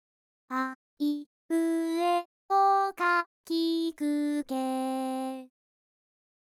VOCALOID6 Editor Liteでも初音ミク V4Xは使用できますが、AIによる自動調整機能には対応していません。